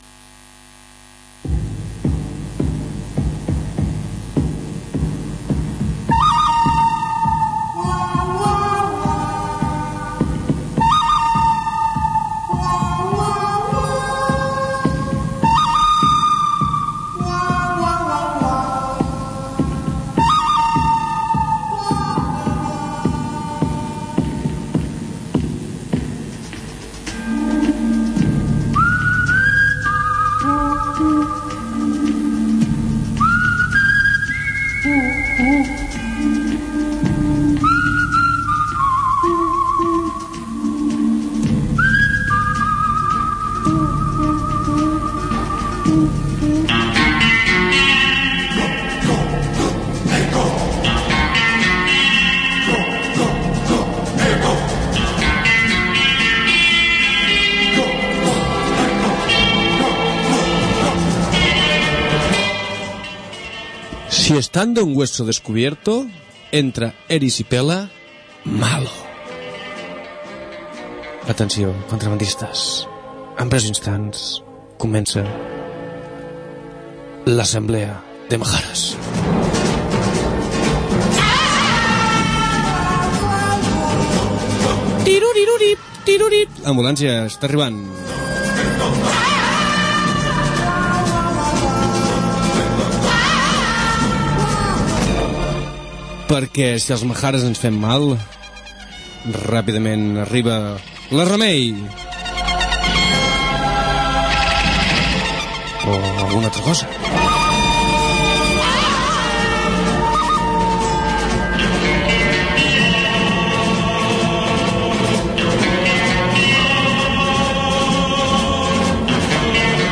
Intentem, sense gaire èxit, anar connectant amb la Marató de Radios Lliures Asturianes realitzada durant el cap de setmana i comentem la jornada sobre la situació del Valle de Laciana al PIC de Can Masdeu.